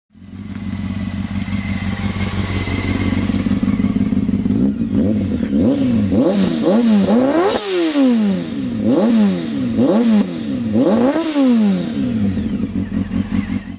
Honda Hornet med MIG-slipon